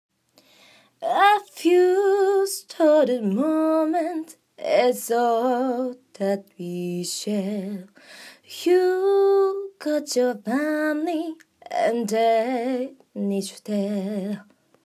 声帯を思いっきり締めた（声帯閉鎖）状態
歌の中でエッジボイスを使って表現すると　↑　参考例
edge-voice1.m4a